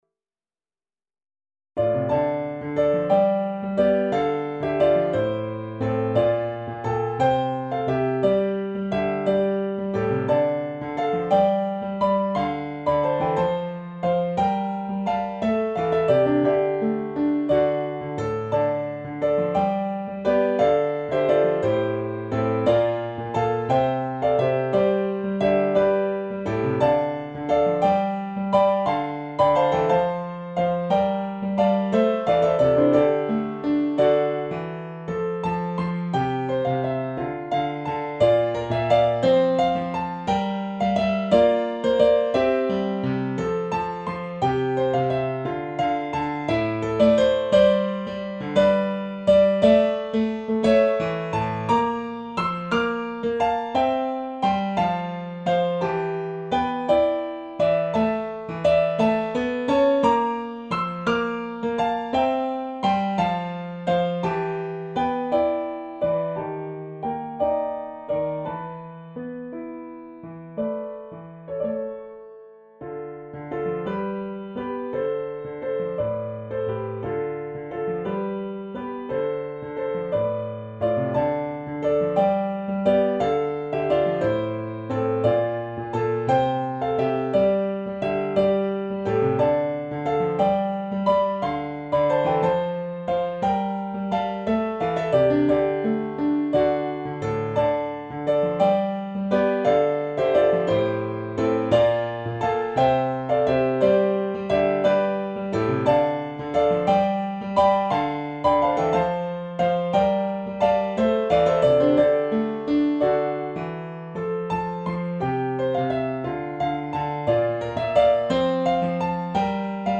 【ピアノ楽譜付き】